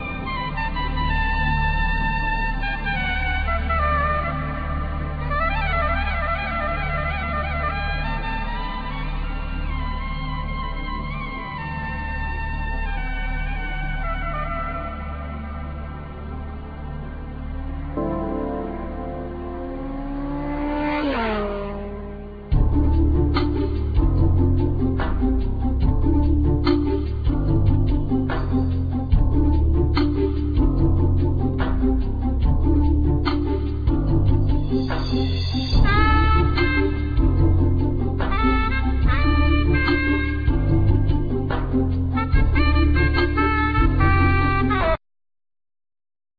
Guitar(Spanish,Electric,Acoustic),Banjo,Percussions
Flauta de adelfa,Tin whistle,Fairlight III
Piano
Cello
Vocals